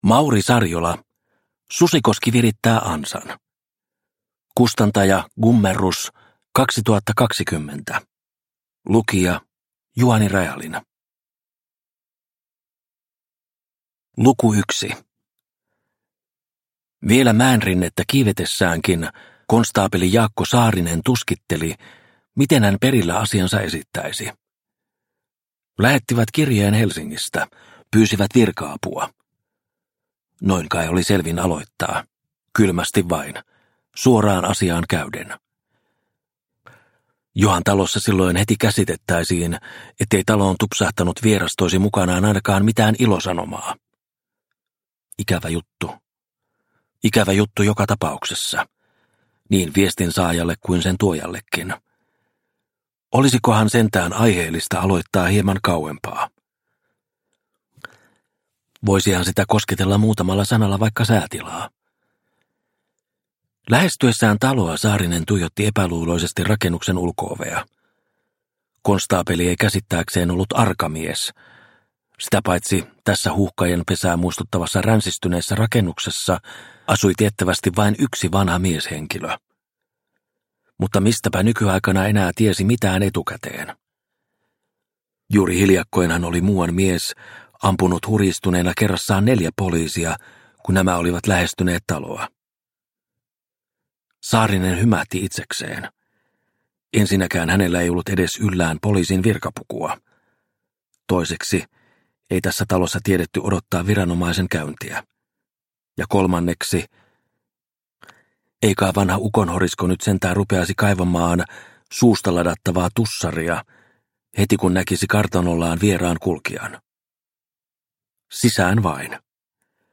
Susikoski virittää ansan – Ljudbok – Laddas ner